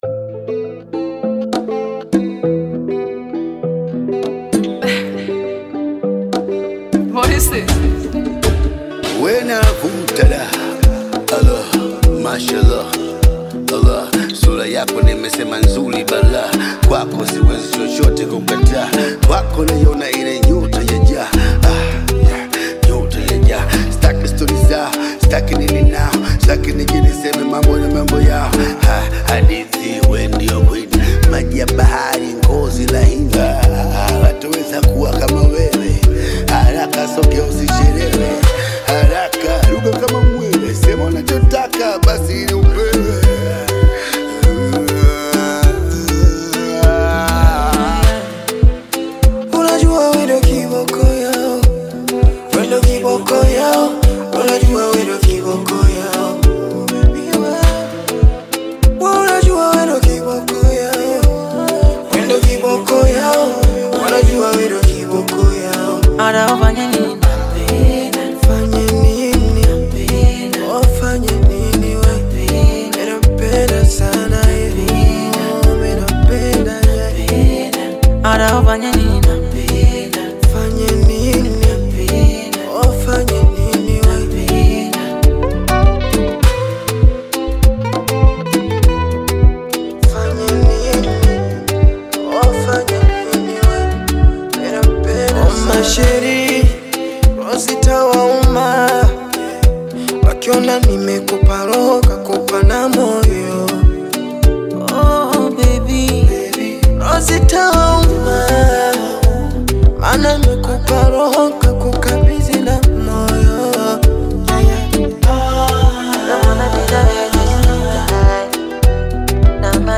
hip-hop
creating a fusion of hip-hop and Bongo Flava.